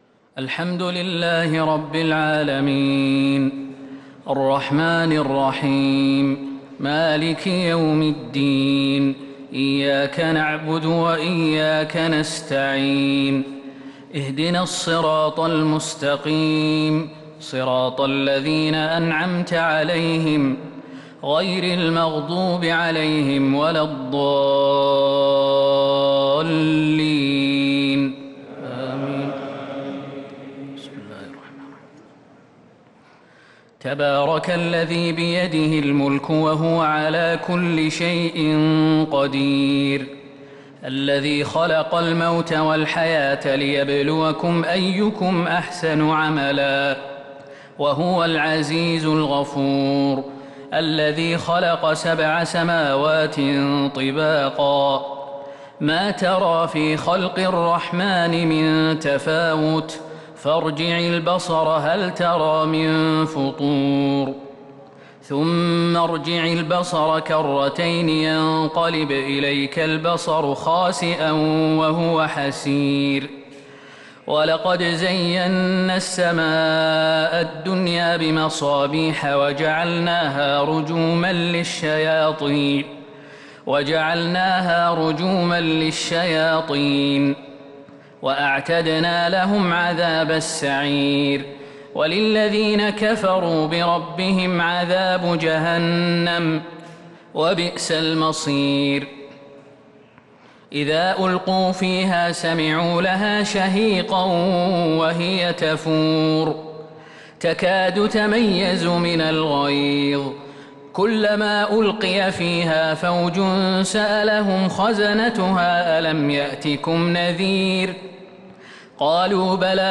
تهجد ليلة 28 رمضان 1443هـ من سورة الملك حتى سورة المزمل | Tahajjud prayer The 28th night of Ramadan 1443H Surah A Al-Mulk to An-Muzzammil > تراويح الحرم النبوي عام 1443 🕌 > التراويح - تلاوات الحرمين